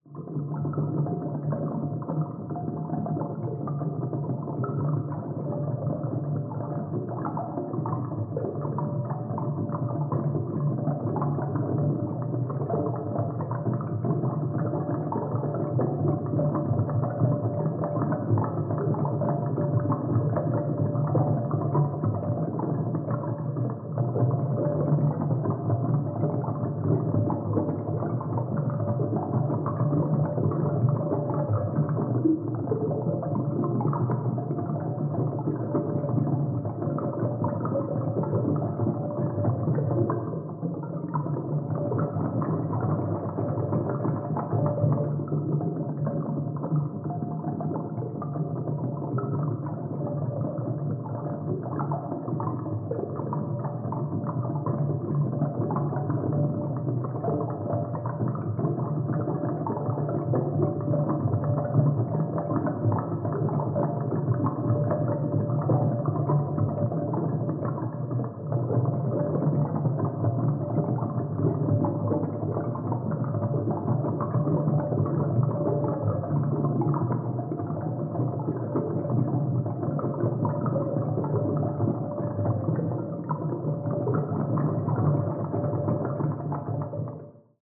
Dive Deep - Small Bubbles 02.wav